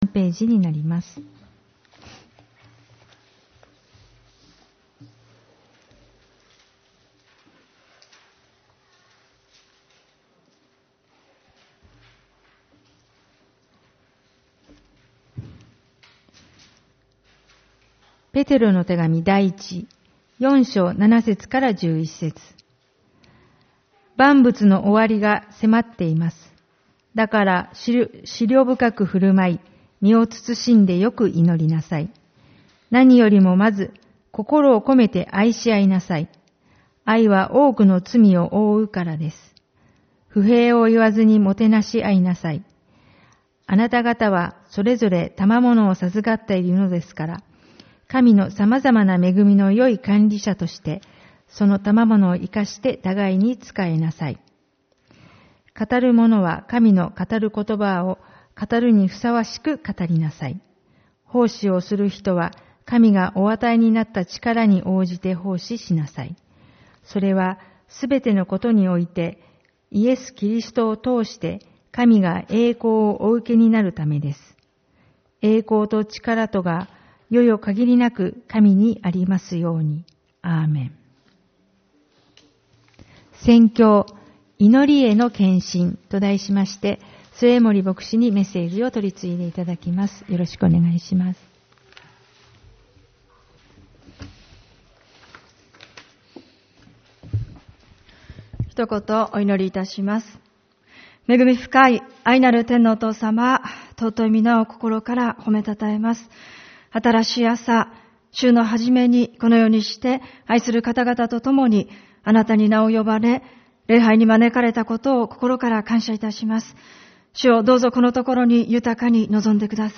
主日礼拝 「祈りへの献身」